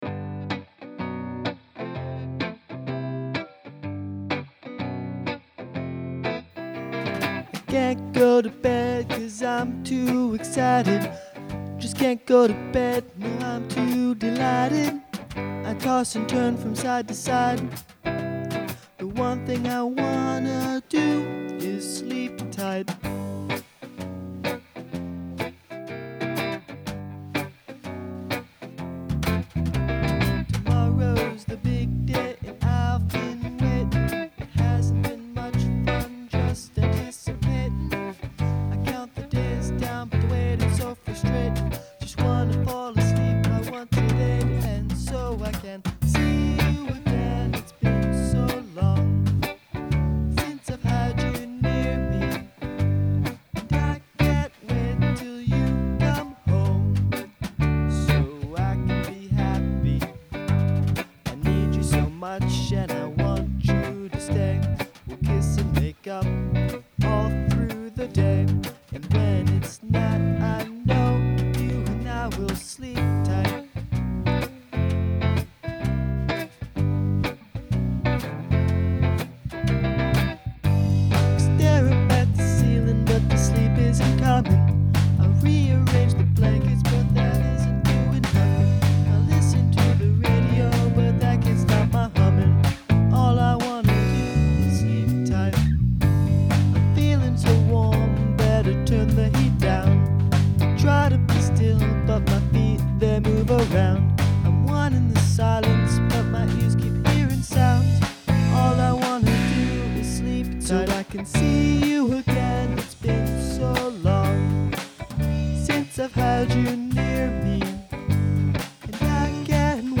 Song must include (human) whistling